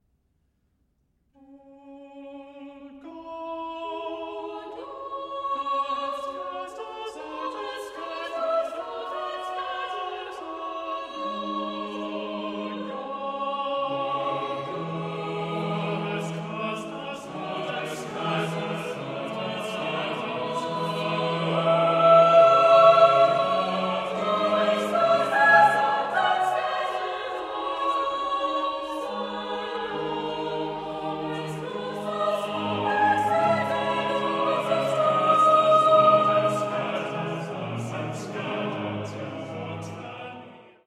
Music of the Baroque Chorus and Instruments
The main types of Restoration anthems are represented in both Purcell’s musical output and this program.
Purcell opens with what might sound like conventional imitation, but the technique matches the text perfectly—as God “cast us out and scattered us abroad,” the angular fugal subject “scatters” the notes.
And as the music also illustrates, full unity is achieved at the end.